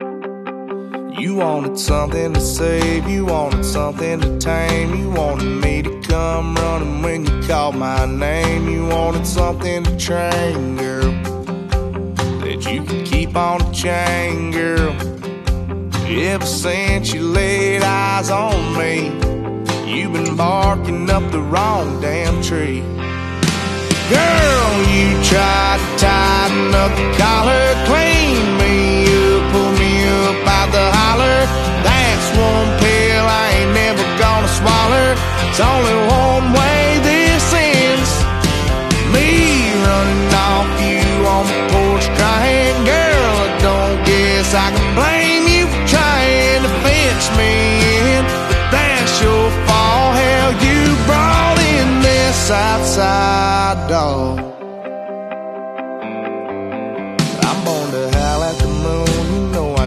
Ouside dog>>> sound effects free download